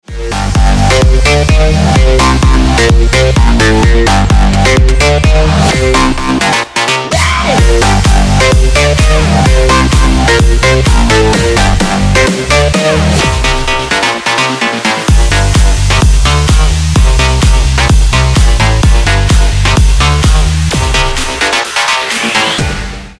• Качество: 320, Stereo
зажигательные
без слов
энергичные
Стиль: progressive house